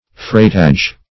Freightage \Freight"age\ (fr[=a]t"[asl]j; 48), n.